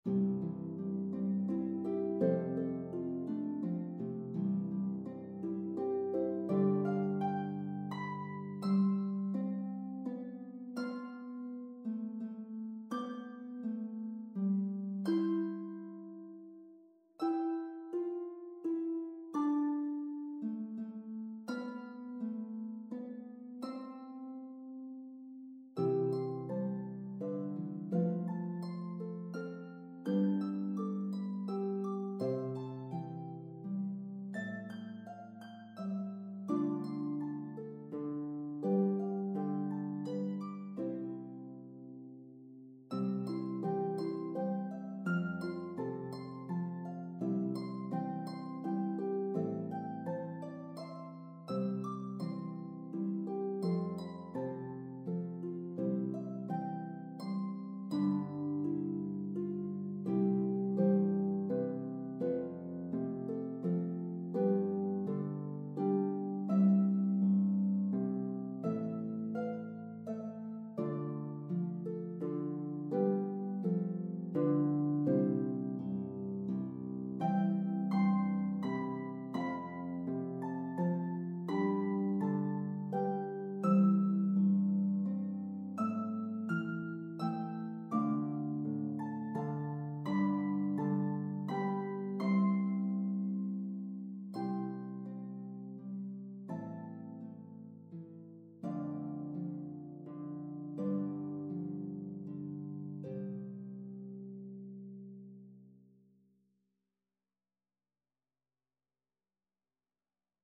It is in the key of C. Dynamics are clearly marked.
Very little is known of this beautiful 16th Century melody